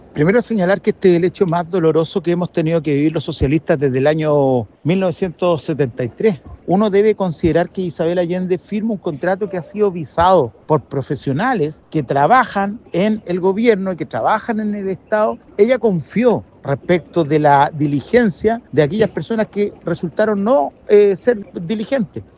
El diputado socialista Nelson Venegas calificó la destitución como “el hecho más doloroso que han enfrentado los socialistas desde el golpe de Estado” y responsabilizó a personeros del gobierno que, según él, validaron el contrato suscrito por la exsenadora.